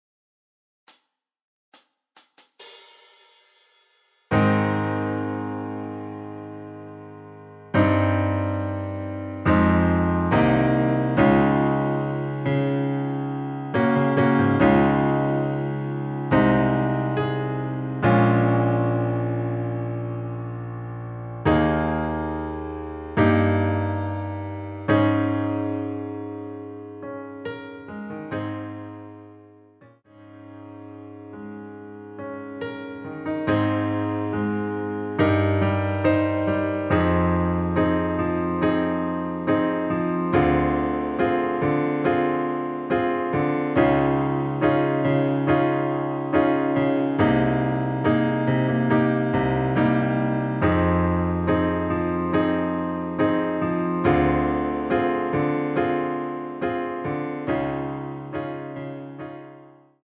반주가 피아노 하나만으로 제작 되었습니다.(미리듣기 확인)
전주없이 노래가시작되는곡이라 카운트 만들어 놓았습니다.
Ab
앞부분30초, 뒷부분30초씩 편집해서 올려 드리고 있습니다.
중간에 음이 끈어지고 다시 나오는 이유는